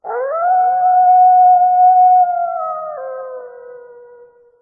loup.ogg